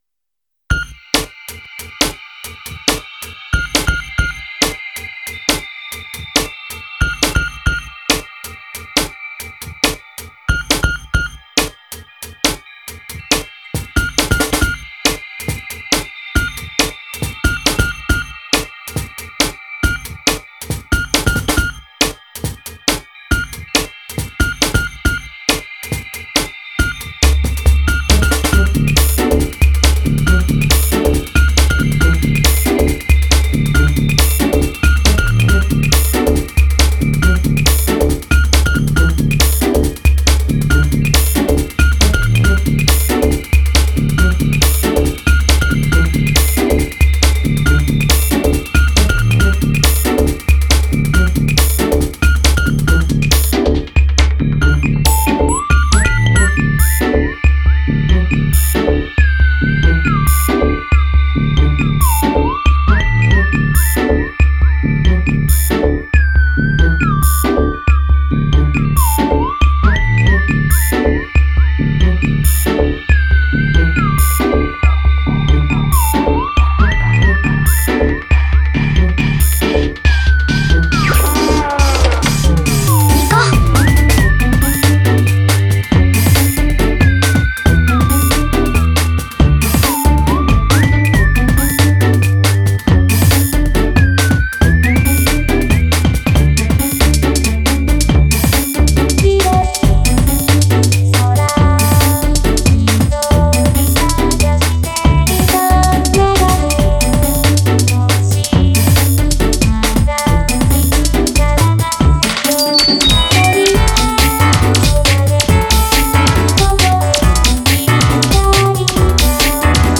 OP remix